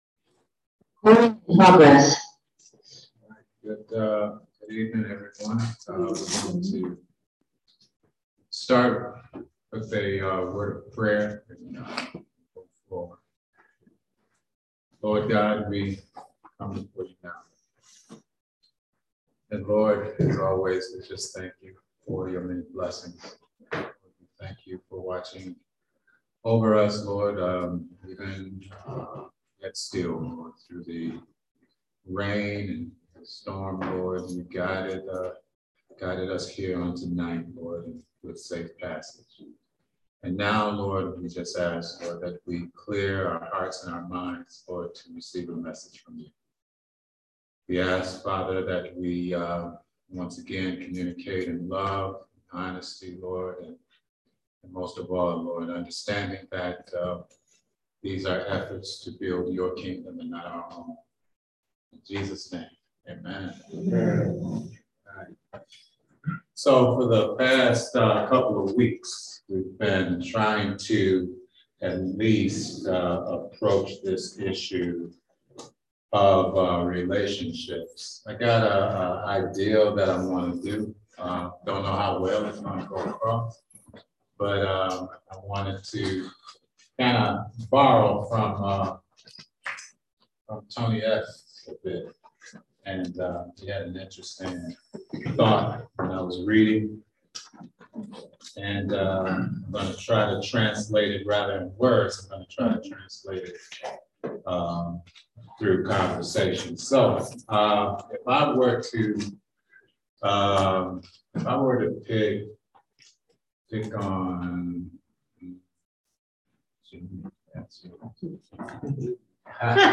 Bible Study - New Life Community Church